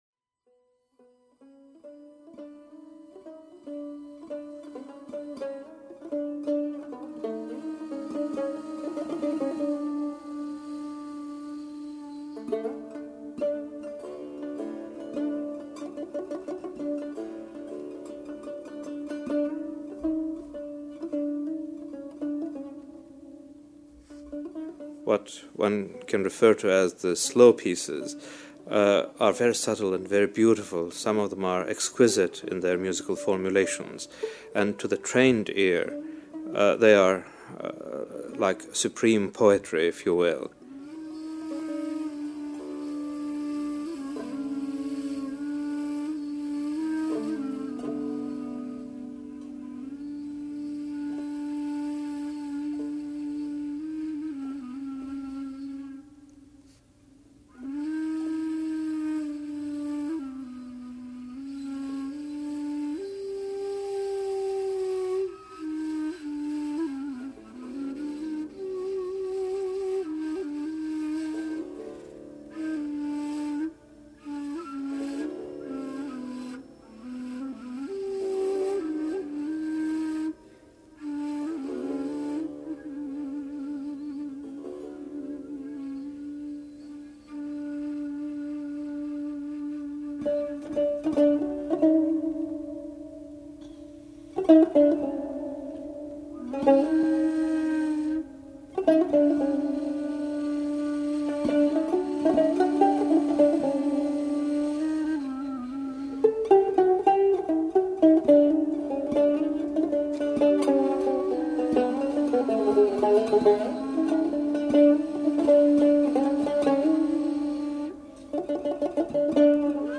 TURKISH   Classical in NYC; bellydance and folk in DC
Turkish.mp3